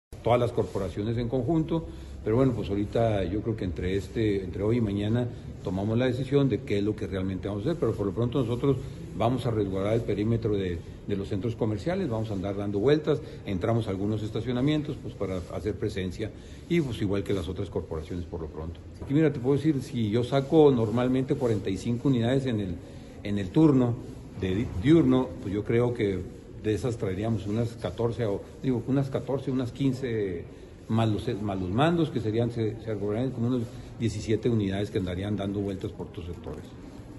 AUDIO: CÉSAR KOMABA , SUBRECRETARÍA DE MOVILIDAD DE LA SECRETARÍA DE SEGURIDAD PÚBLICA DE ESTADO (SSPE)